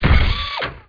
step2.wav